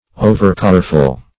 Overcareful \O"ver*care"ful\ ([=o]"v[~e]r*k[^a]r"f[.u]l), a.